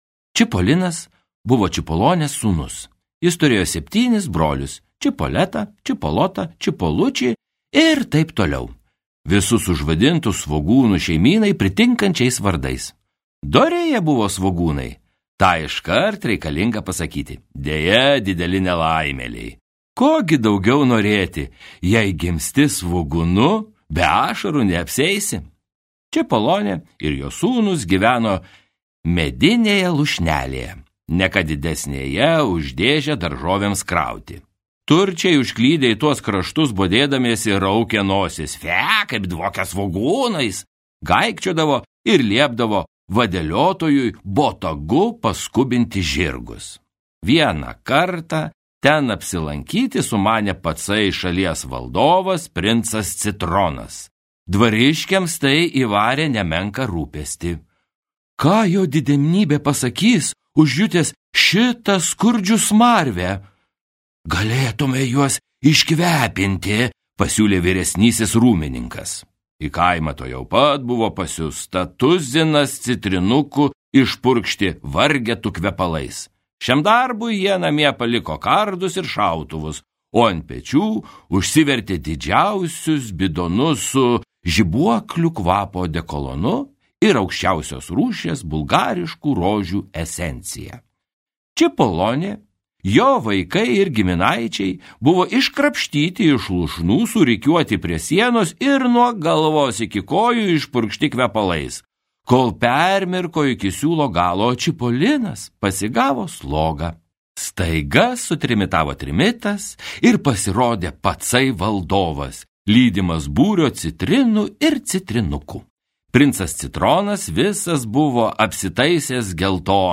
Čipolino nuotykiai | Audioknygos | baltos lankos